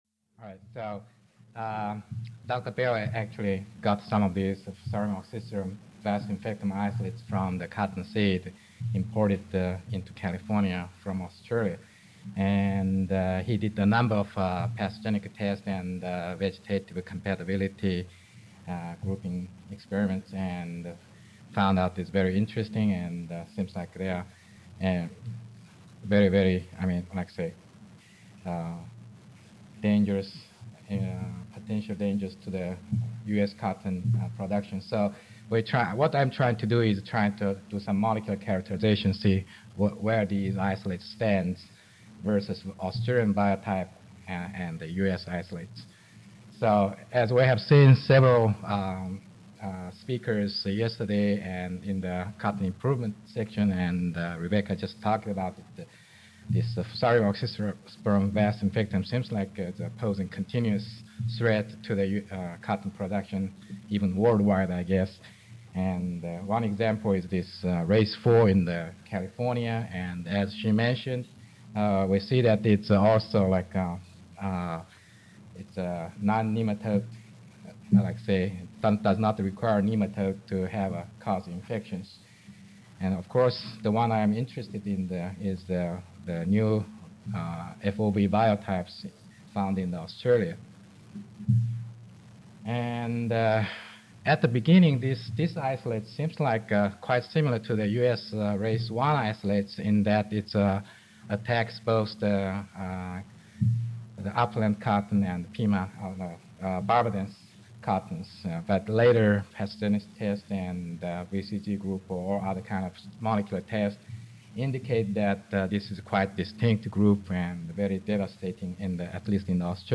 Session: Cotton Disease Council - Friday Morning Session
Characterization of Fusarium oxysporum f. sp. vasinfectum Isolates from Cottonseed Imported from Australia into California for Dairy Feed Recorded presentation